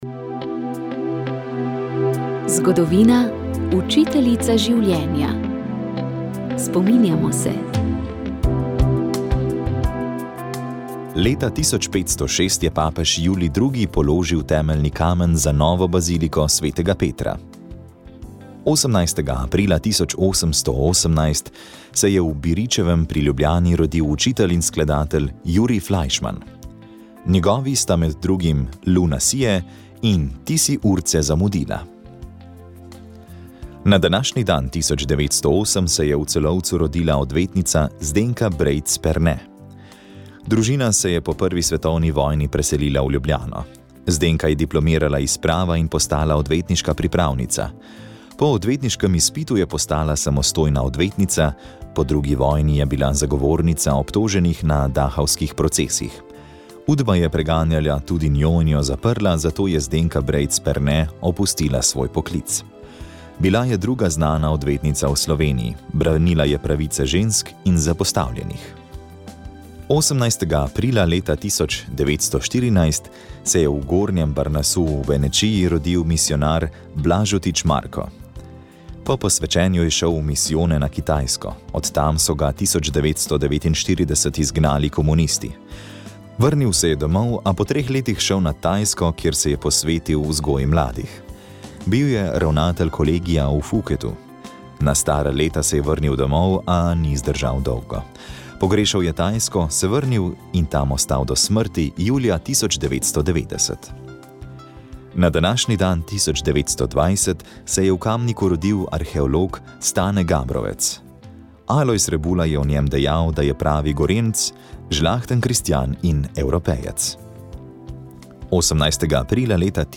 sta nas pospremila skozi mestna gozda Ragov Log in Portoval.